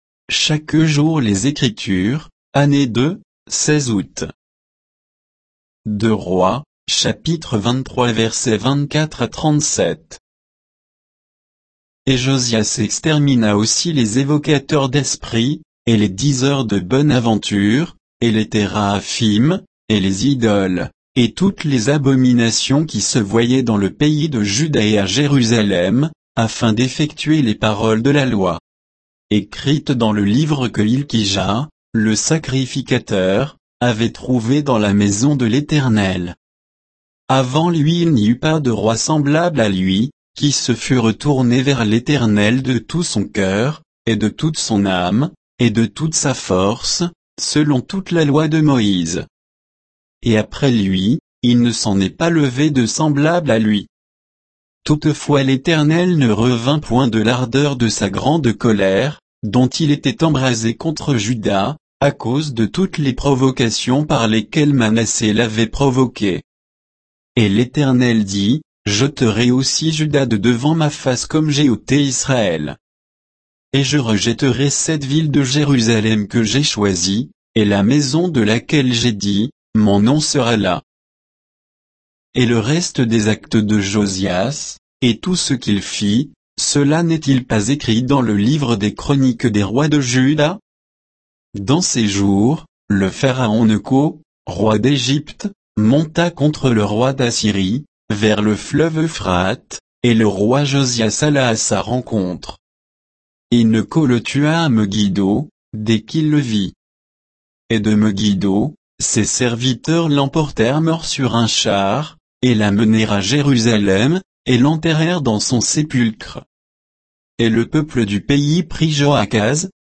Méditation quoditienne de Chaque jour les Écritures sur 2 Rois 23